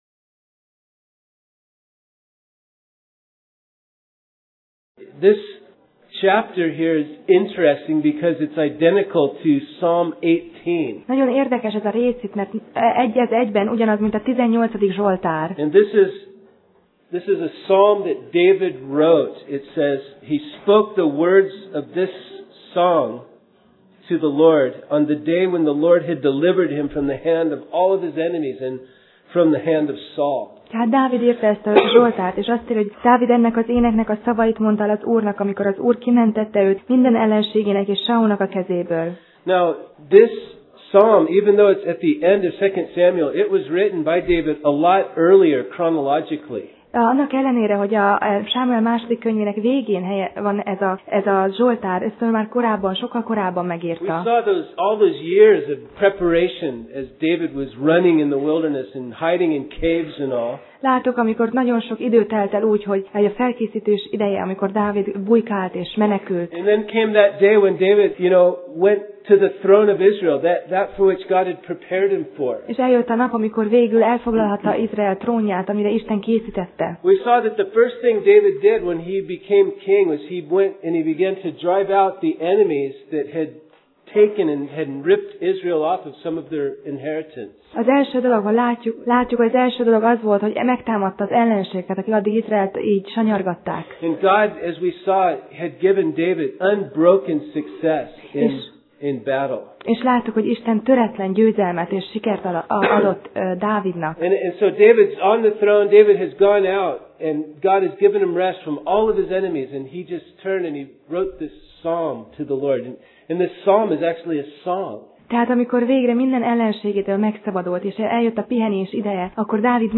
Alkalom: Szerda Este